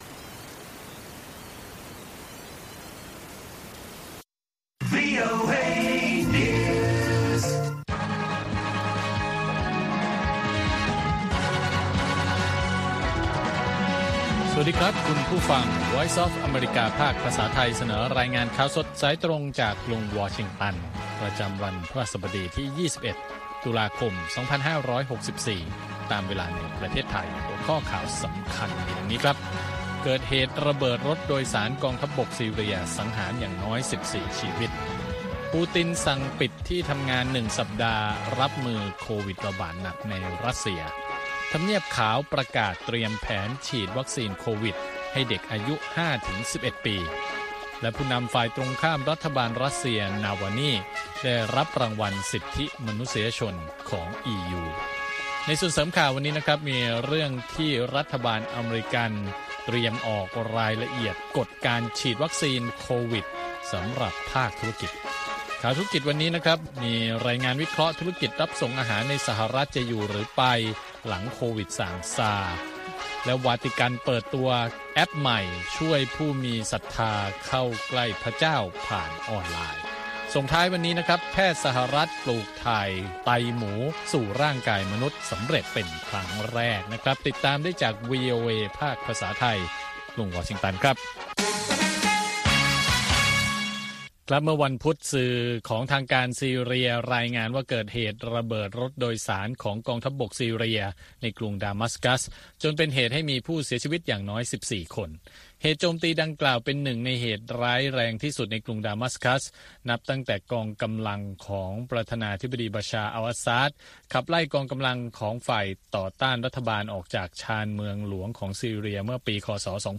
ข่าวสดสายตรงจากวีโอเอ ภาคภาษาไทย 6:30 – 7:00 น. ประจำวันพฤหัสบดีที่ 21 ตุลาคม 2564 ตามเวลาในประเทศไทย